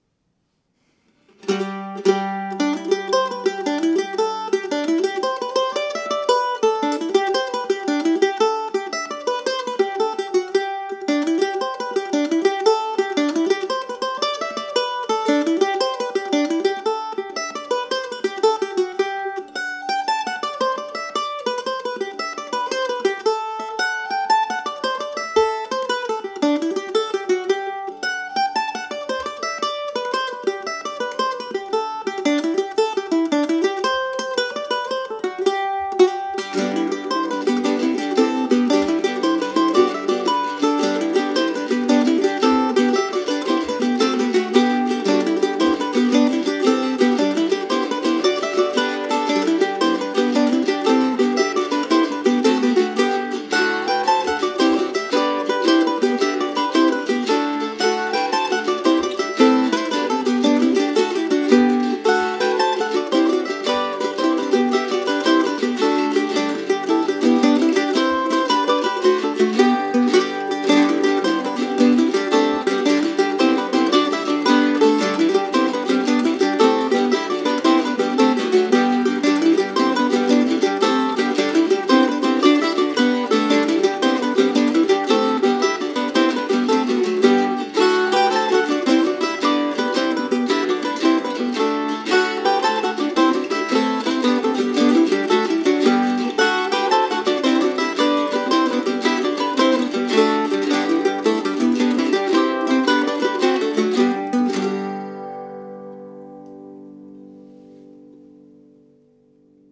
So today's simple little jig is called "The Hoosier Clockmaker" in recognition of the life and memory of Dad.